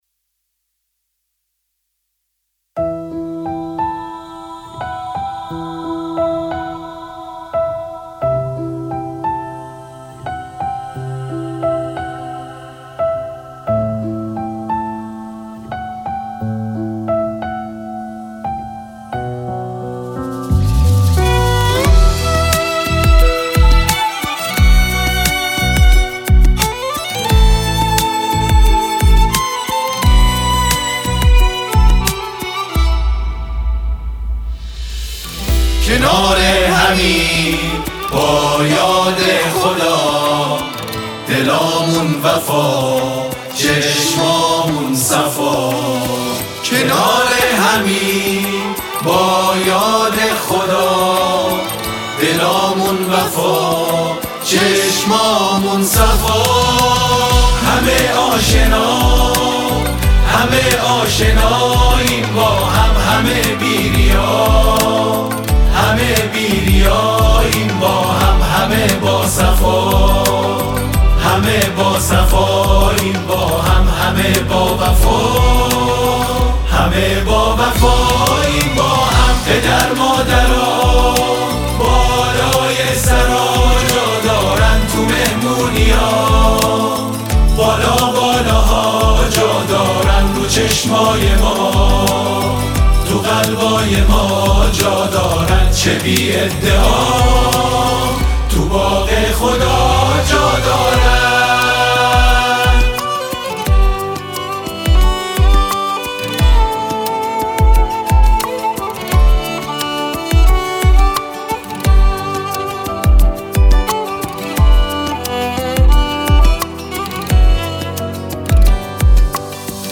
گروه هم آوایی